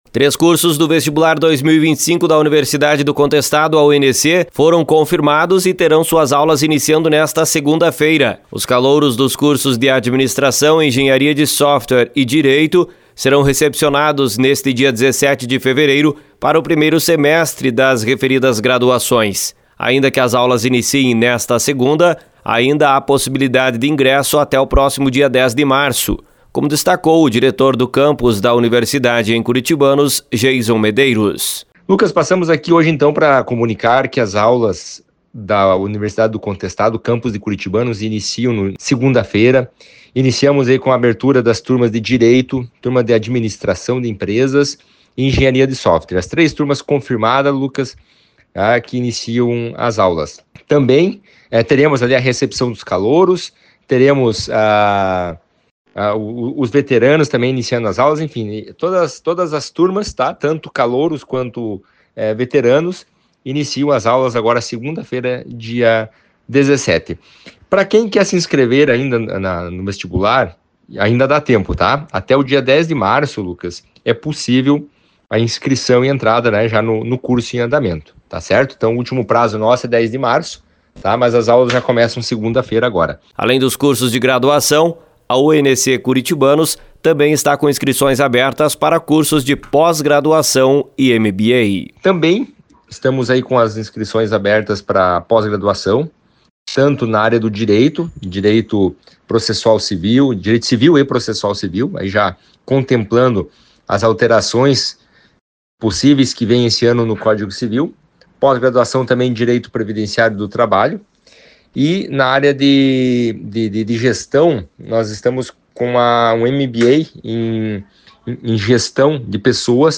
Informações com Repórter